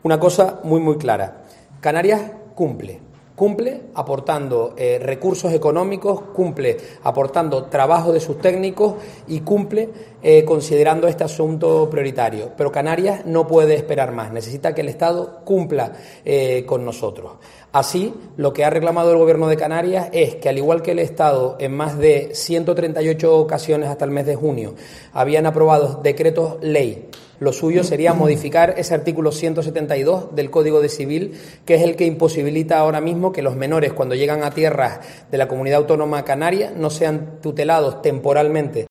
Alfonso Cabello, portavoz del Gobierno de Canarias
En una rueda de prensa para dar cuenta de los acuerdos del Consejo de Gobierno, ha dicho que "basta de excusas" porque hace falta una "respuesta sólida" y el Gobierno central ya ha aprobado hasta 138 decretos ley en el ámbito estatal.